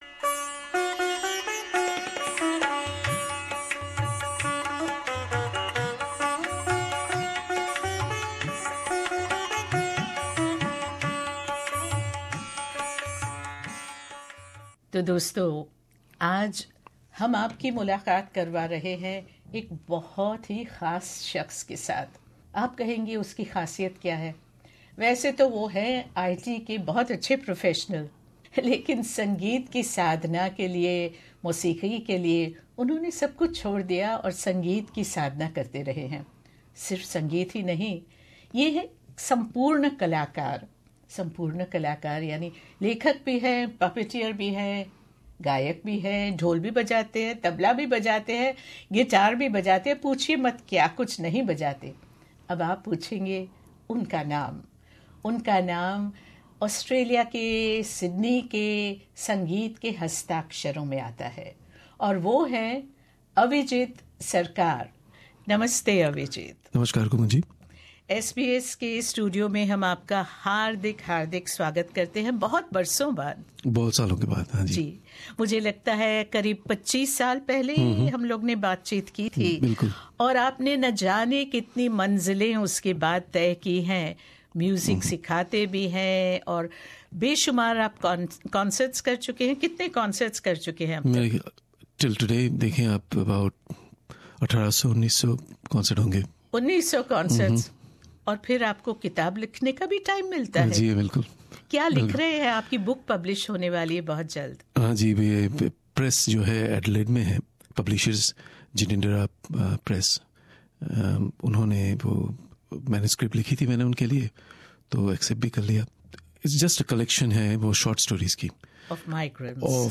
अपनी गायकी के नमूने भी पेश कर रहे हैं